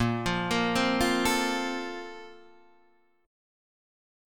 A# Major 7th Suspended 2nd